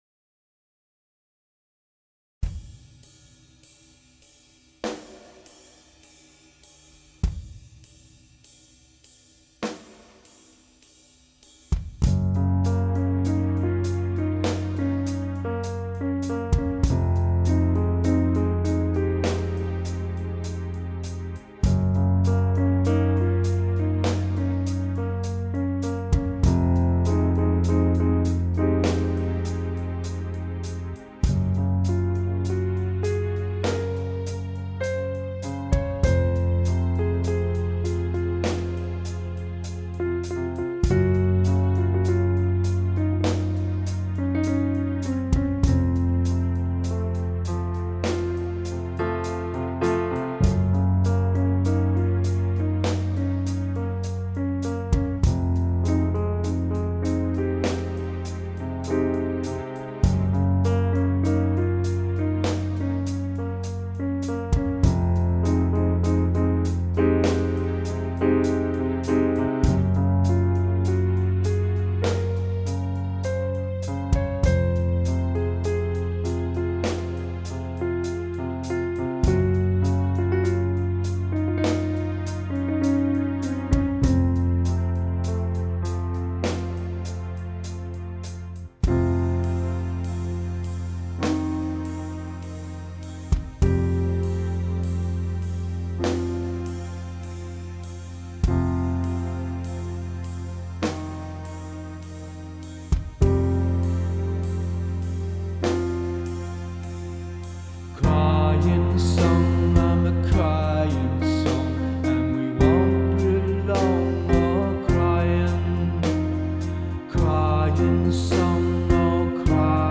6:02/100,105,108bpm